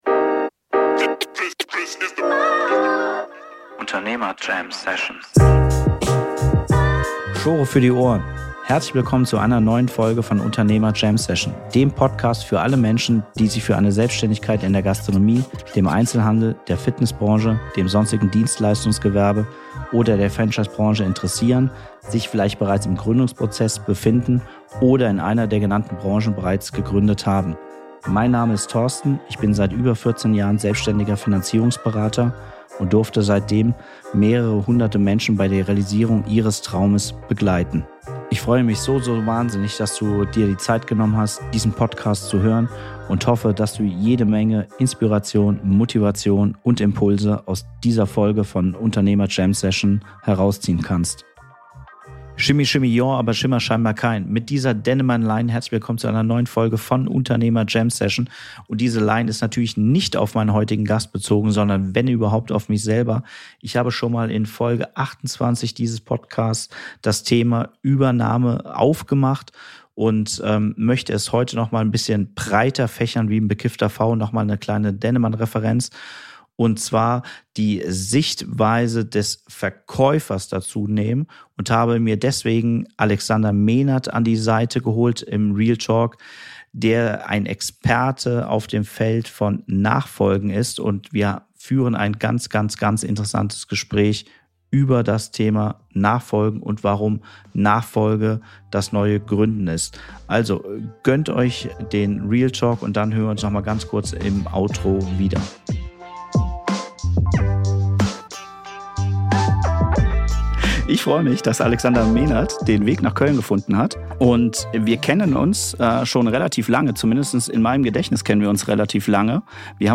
In der heutigen Podcastfolge beleuchten wir den Prozess sowohl aus dem Blickwinkel des Käufers sowie des Verkäufers. Und diesmal mache ich das nicht allein.